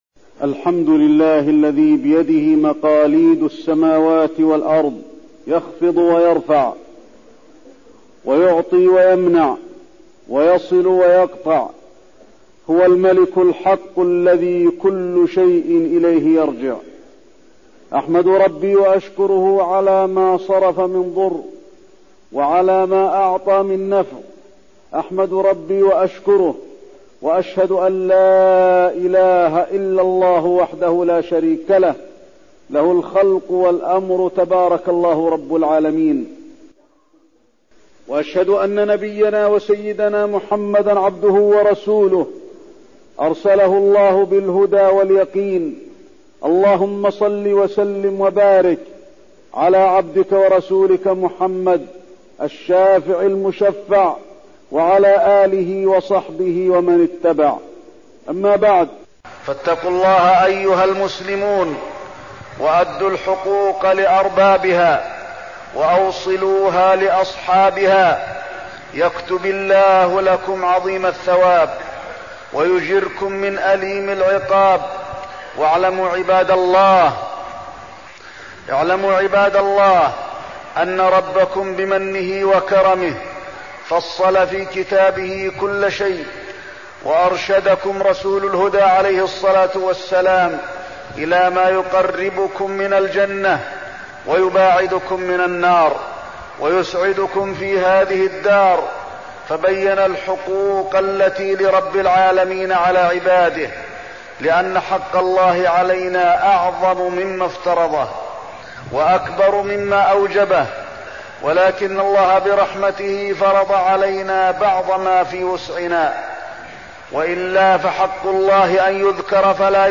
تاريخ النشر ١ ربيع الأول ١٤١٦ هـ المكان: المسجد النبوي الشيخ: فضيلة الشيخ د. علي بن عبدالرحمن الحذيفي فضيلة الشيخ د. علي بن عبدالرحمن الحذيفي صلةالرحم